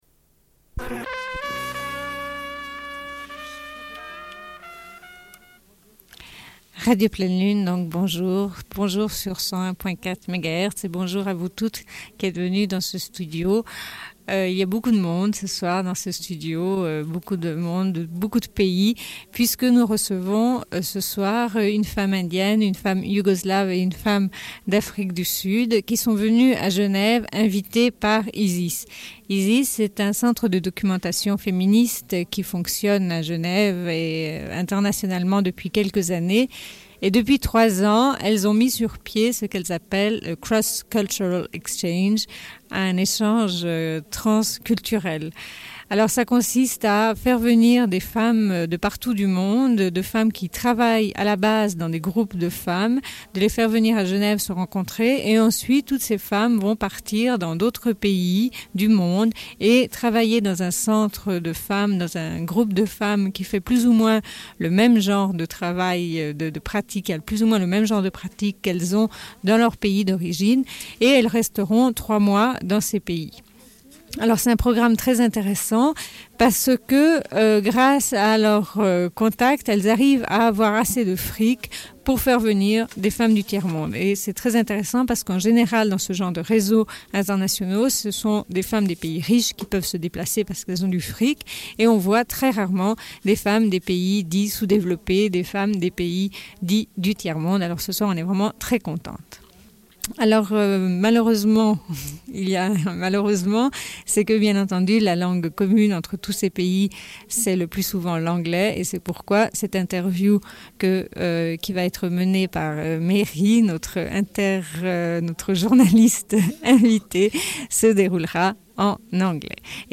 Une cassette audio, face A31:20
Émission avec trois femmes invitées par ISIS : une femme indienne, une femme sud-africaine et une femme yougoslave. « Cross cultural exchange », invitation de femmes actives dans des groupes femmes et possibilité de voyager et de travailler dans des groupes femmes d'autres pays. Interview en anglais.